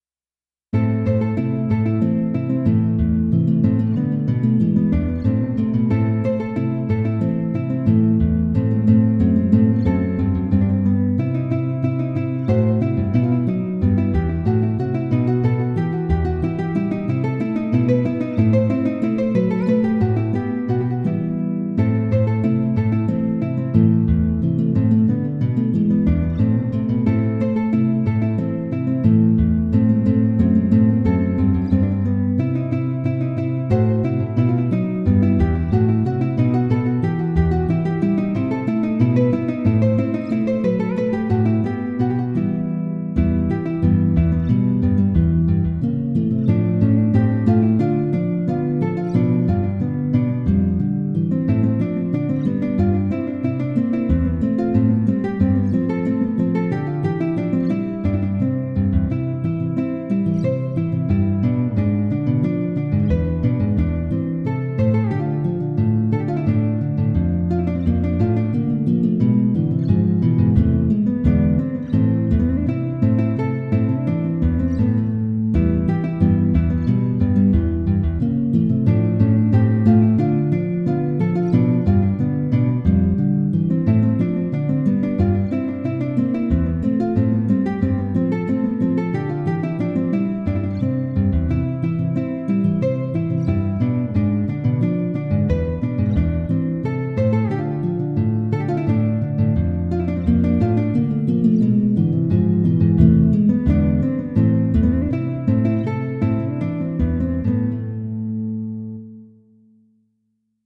�l�h�c�h�@�N���V�b�N�M�^�[�@�o�f�B�l���iBadinerie�jSuite2.(BMV 1067)